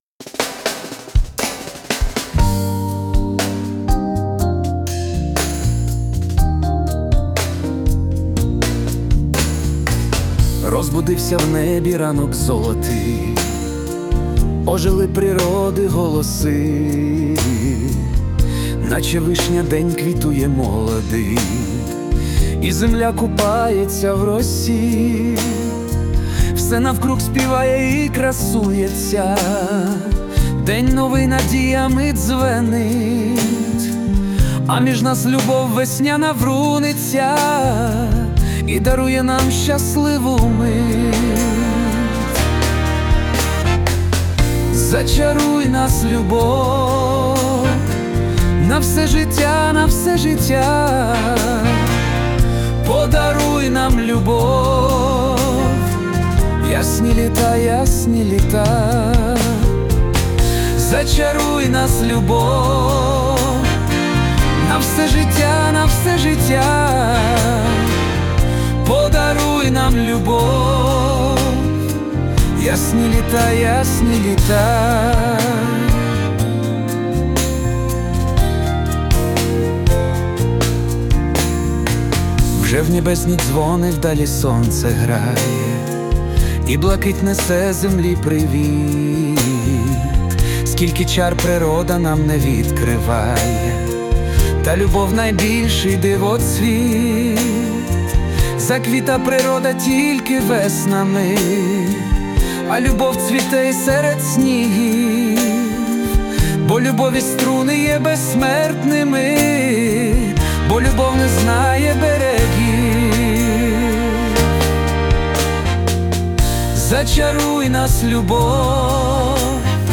Всі мінусовки жанру Pop-UA
Плюсовий запис